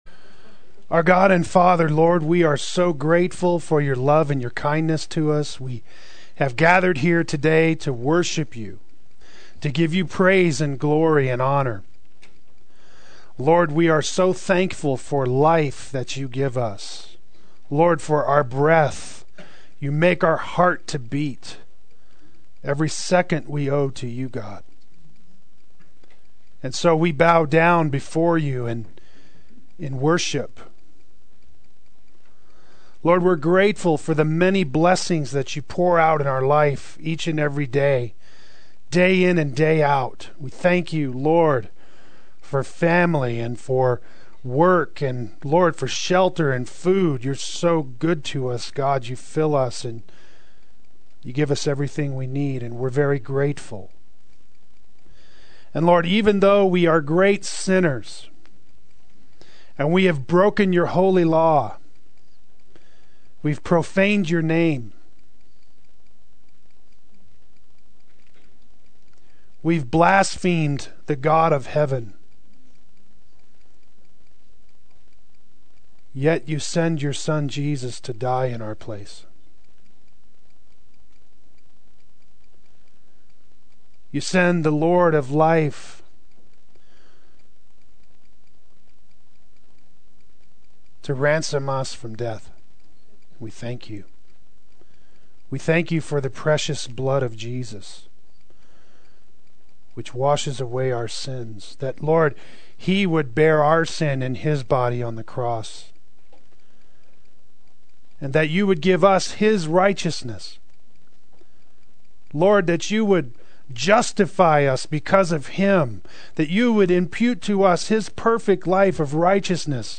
Pride to Humility Adult Sunday School